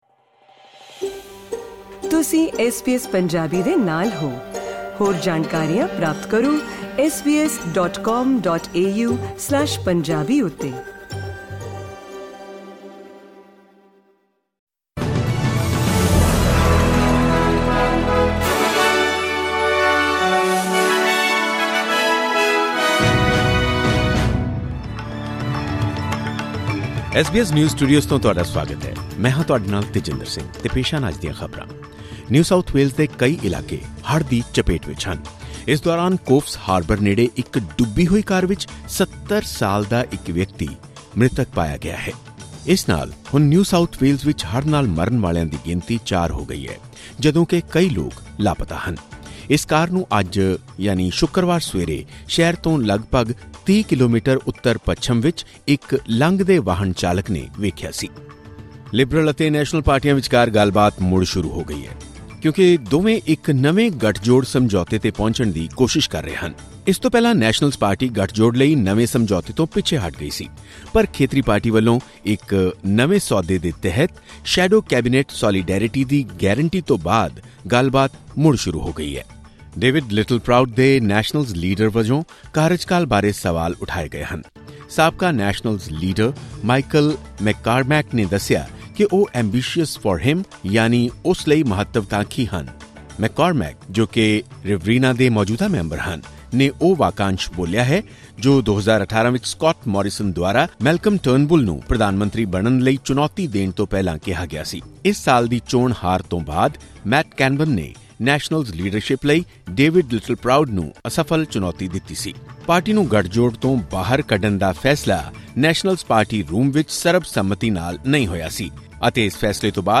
ਖਬਰਨਾਮਾ: ਨਿਊ ਸਾਊਥ ਵੇਲਜ਼ ਦੇ ਕਈ ਇਲਾਕੇ ਹੜ੍ਹਾਂ ਦੀ ਚਪੇਟ ਵਿਚ, ਚਾਰ ਵਿਅਕਤੀਆਂ ਦੀ ਮੌਤ